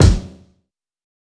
kits/Cardiak/Kicks/TC3Kick22.wav at main
TC3Kick22.wav